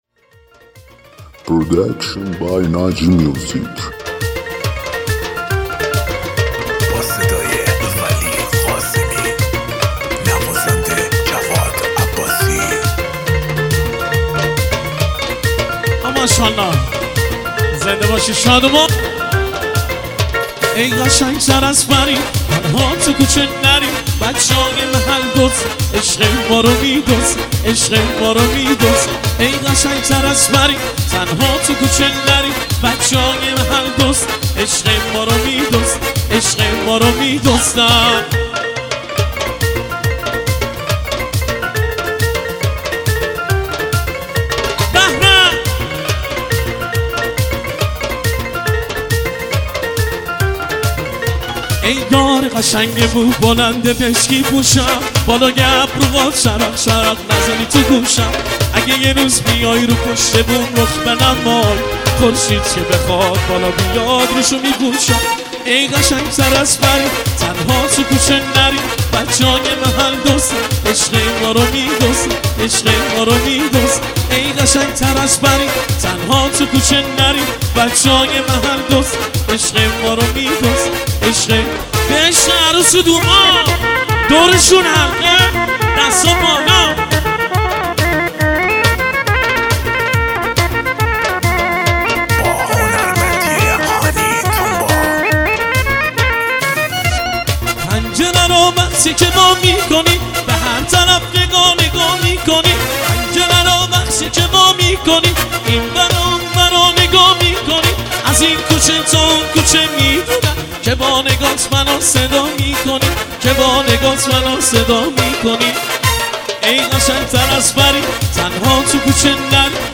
ریمیکس
ریمیکس رقصی خفن مخصوص عروسی و جشن ها